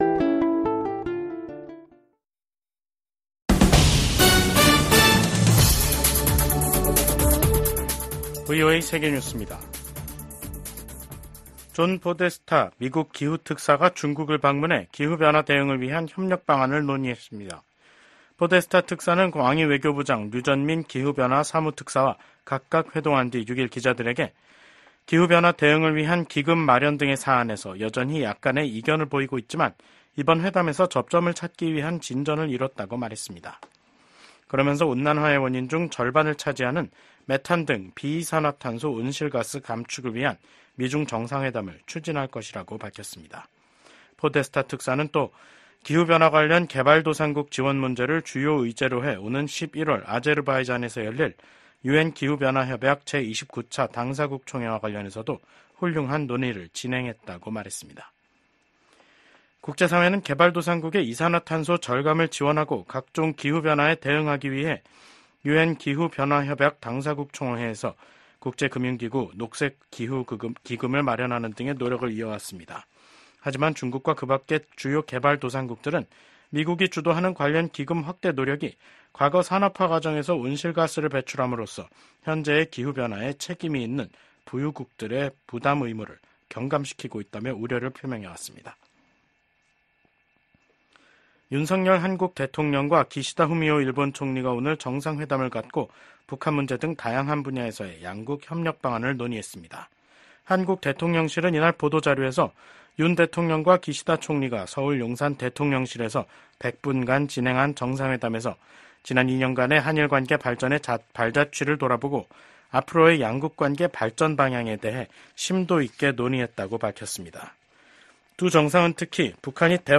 VOA 한국어 간판 뉴스 프로그램 '뉴스 투데이', 2024년 9월 6일 3부 방송입니다. 윤석열 한국 대통령은 퇴임을 앞두고 방한한 기시다 후미오 일본 총리와 정상회담을 가졌습니다. 이에 대해 미국 국무부는 미한일 3국 관계가 공동 안보와 이익에 매우 중요하다고 강조했습니다. 미한 양국이 북한 핵 위협에 대비한 시나리오를 고위급 회담인 확장억제전략협의체 회의에서 논의한 것은 중대한 진전이라는 전문가들의 평가가 나왔습니다.